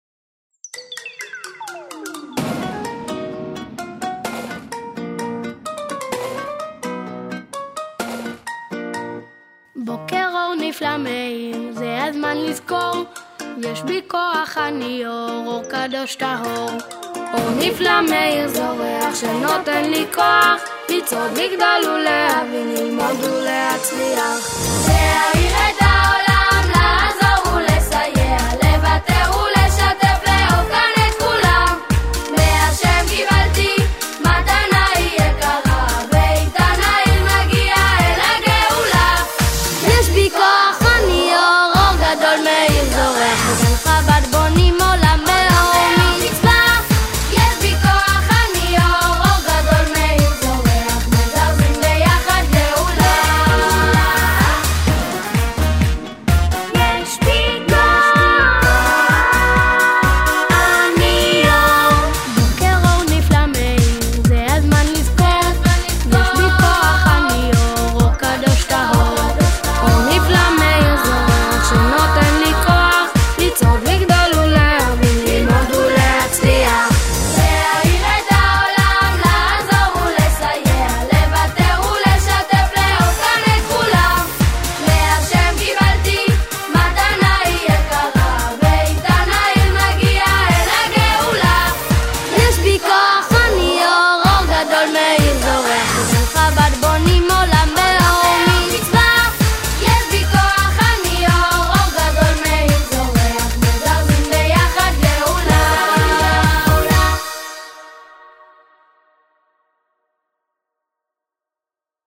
• המנון